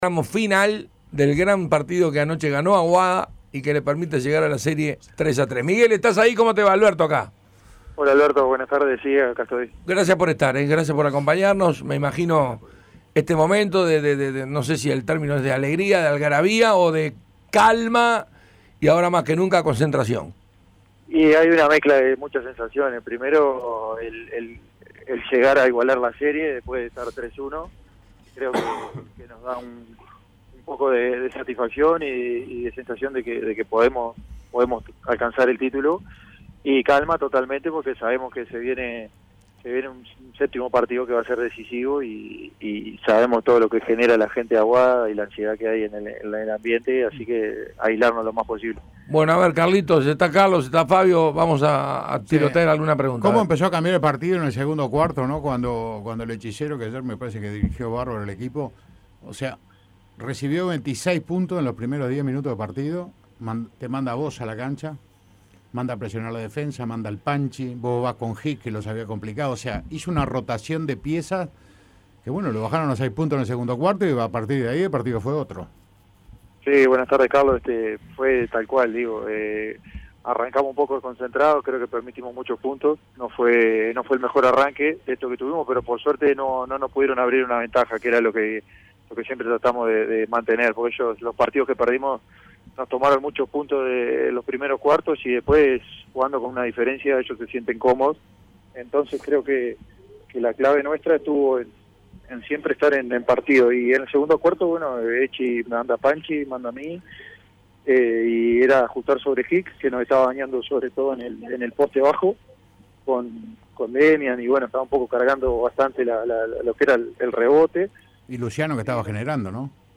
Entrevista completa.